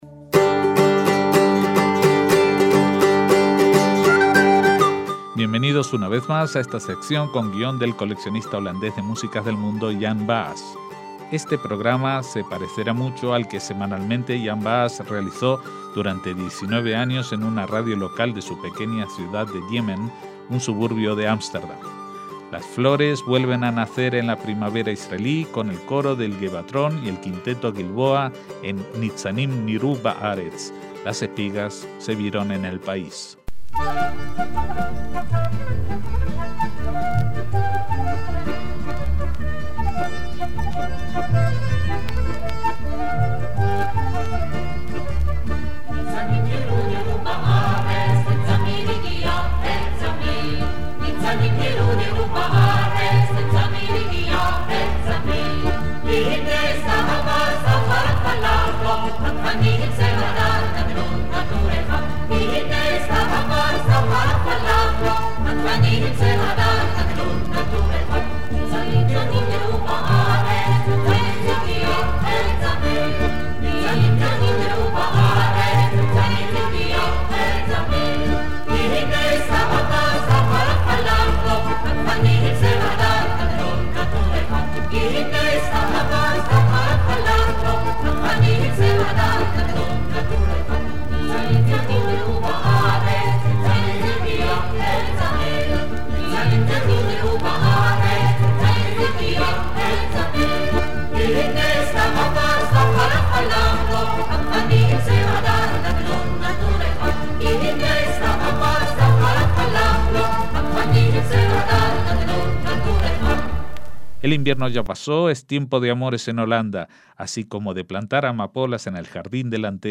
Y, si la semana pasada hablábamos de las canciones judías sobre la llegada de la estación primaveral, hoy seguiremos regocijándonos en distintos repertorios de este momento del año que simboliza el renacer de la vida.